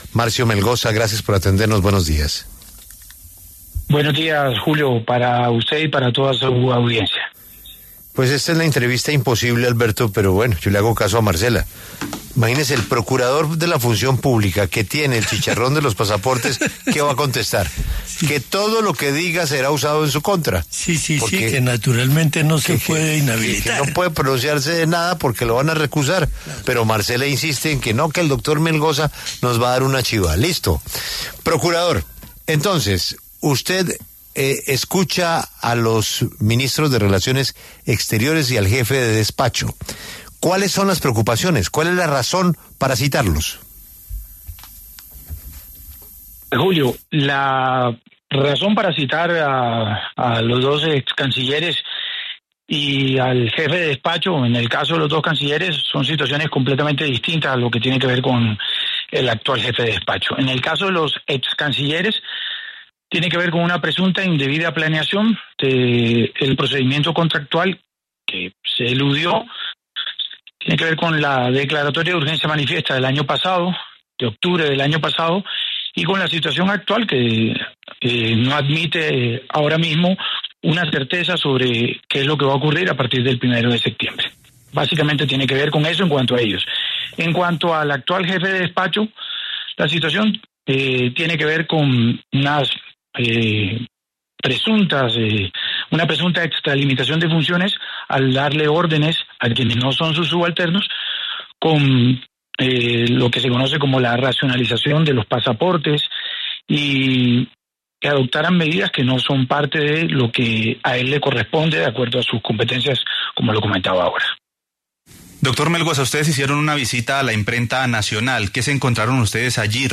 En entrevista con La W, el procurador delegado para la Vigilancia de la Función Pública, Marcio Melgosa, quien ha estado al frente del seguimiento a la situación de los pasaportes, aseveró de forma contundente que, a corte de hoy, la Imprenta Nacional no tiene la capacidad de asumir la impresión de los pasaportes tal como lo busca el Gobierno.